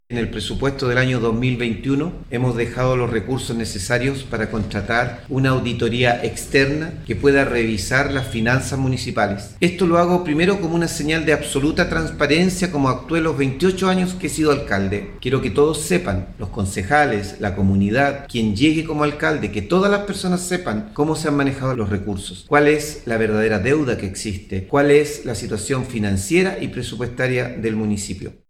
01-ALCALDE-MELLA-Anuncia-auditoria.mp3